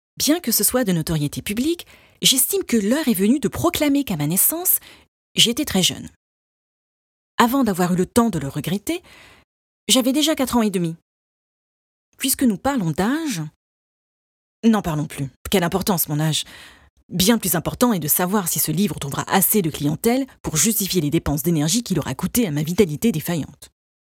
Livre audio pour enfants